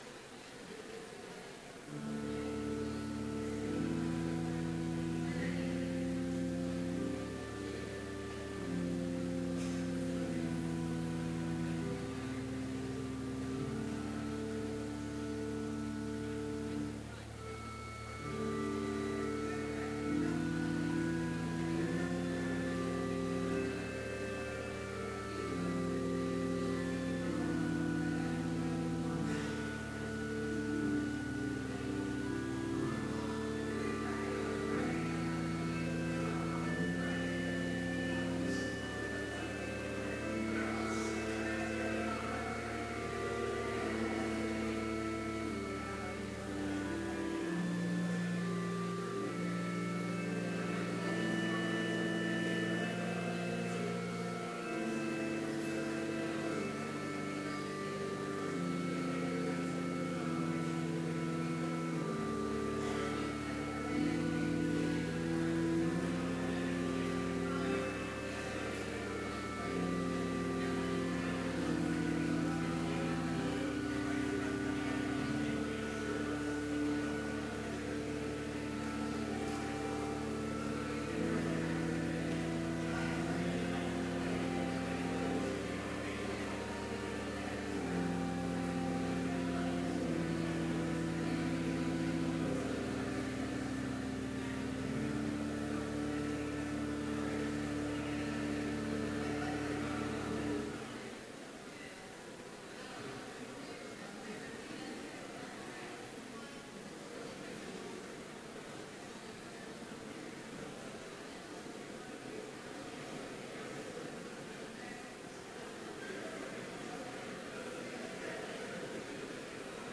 Complete service audio for Chapel - November 4, 2015
Prelude Hymn 525, vv. 1-4, I Know of a Sleep In Jesus' Name Reading: Matthew 9:18-25 Devotion Prayer Hymn 525, vv. 5-7, God's Son to our graves… Blessing Postlude